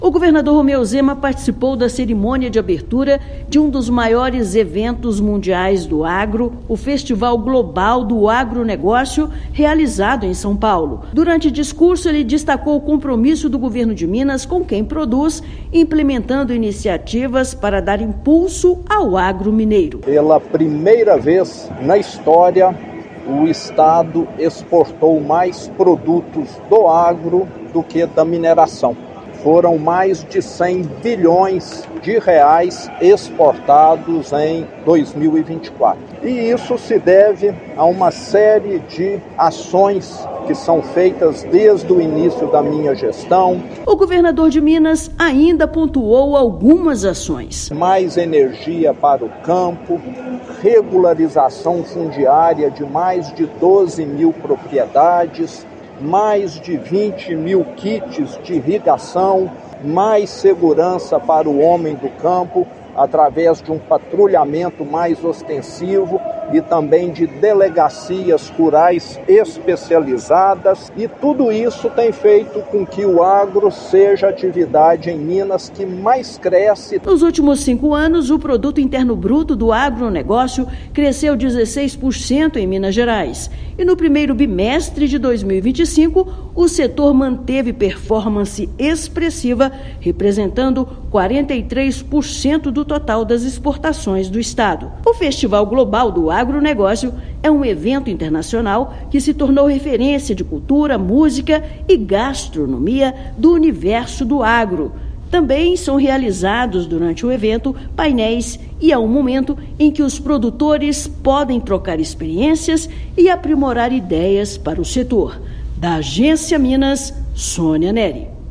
No Global Agribusiness Festival 2025, chefe do Executivo estadual fez um balanço da evolução do segmento em Minas e os benefícios acarretados, principalmente para os pequenos produtores. Ouça matéria de rádio.